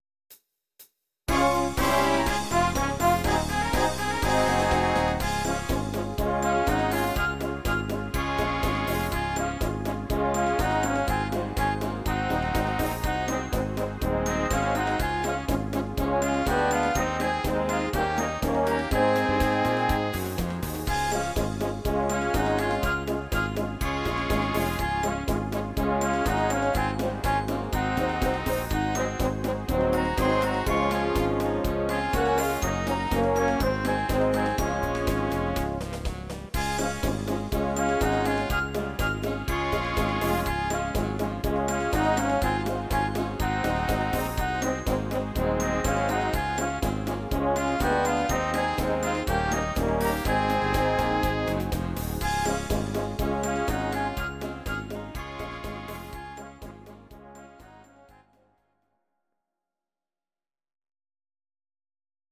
These are MP3 versions of our MIDI file catalogue.
Please note: no vocals and no karaoke included.
instr. Orchester